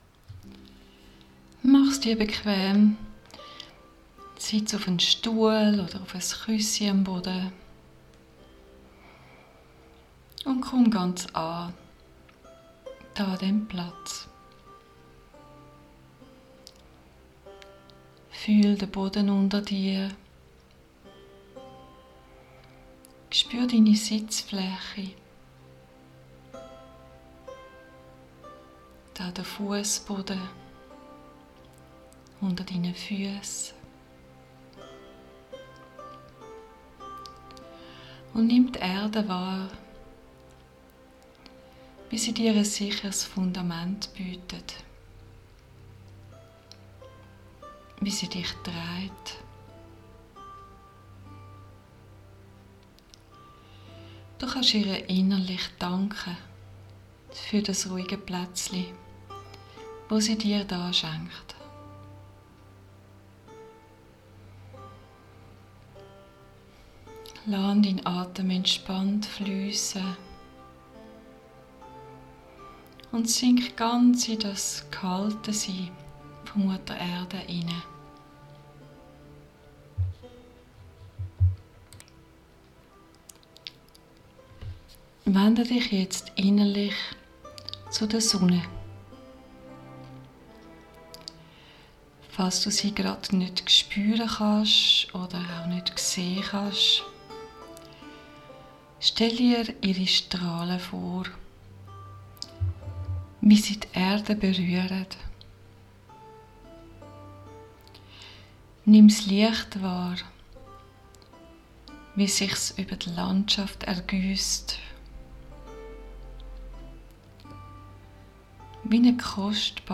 Diese Meditation hilft, mit der höheren, göttlichen Sonne in Kontakt zu kommen und schenkt Ausgleich und Heilung. (Schweizerdeutsch gesprochen.) 10 Minuten